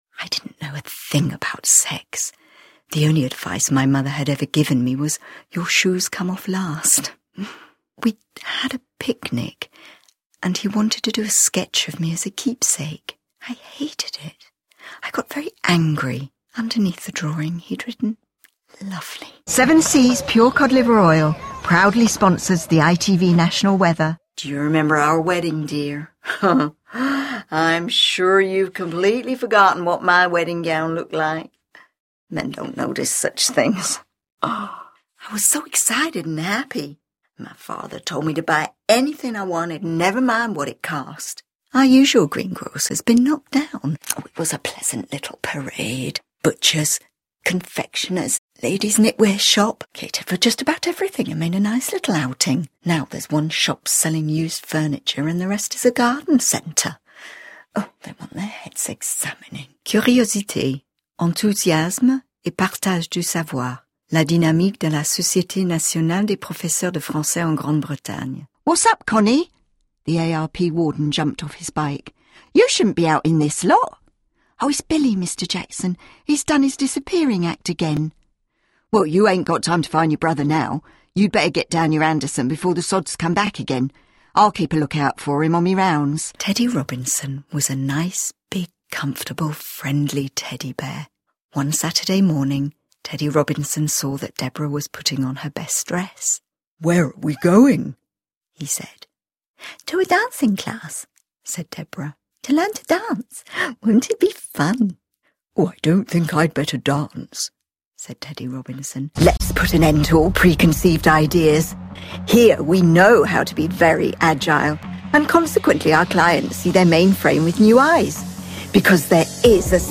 Voix off
Bande démo voix